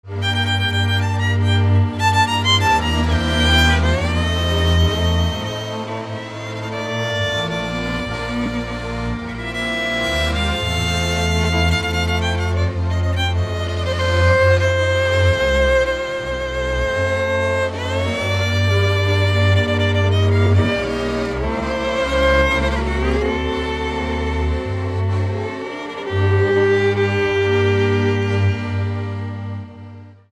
Dallampélda: Hangszeres felvétel
Erdély - Háromszék vm. - Őrkő (Sepsiszentgyörgy)
hegedű
kontra (háromhúros)
harmonika
bőgő
Stílus: 1.3. Ereszkedő moll népies műdalok